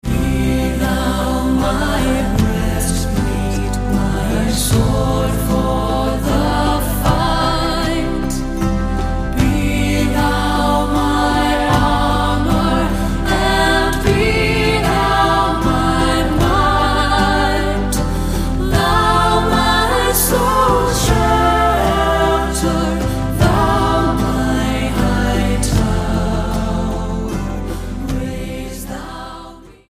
uilleann piper